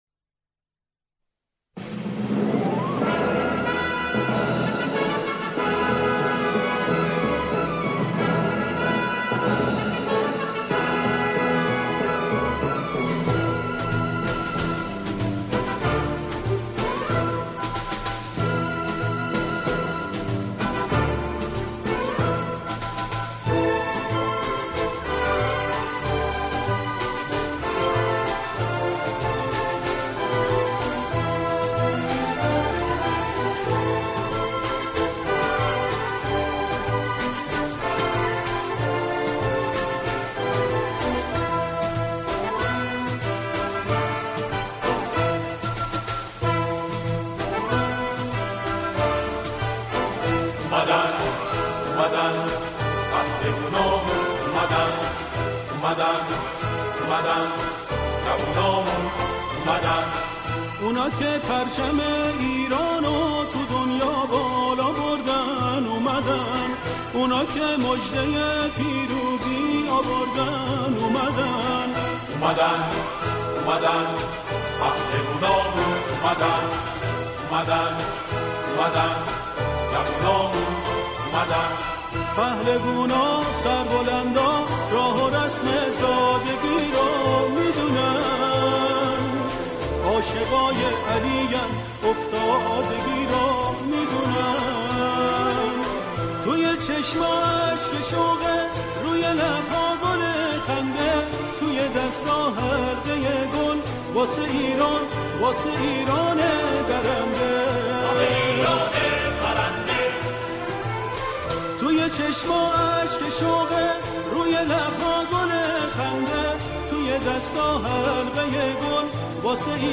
سرودهای ورزشی